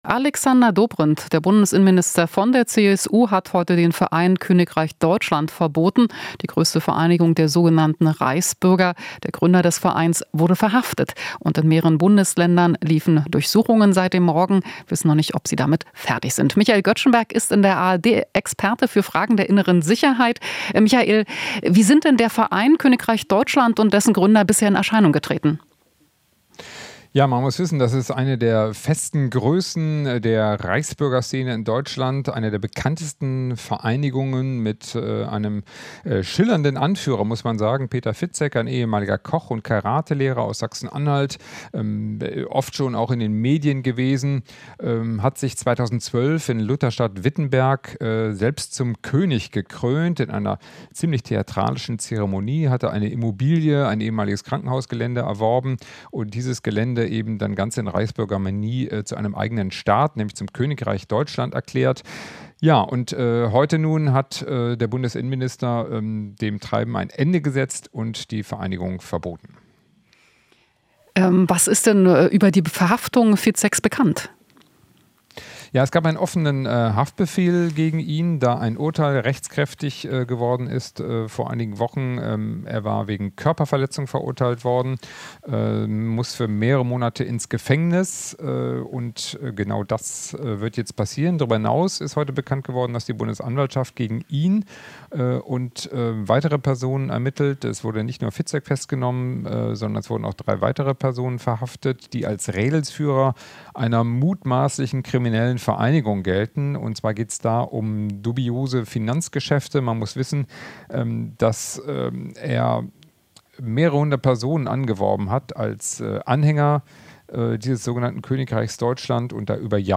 Interview - Vereinigung "Königreich Deutschland" verboten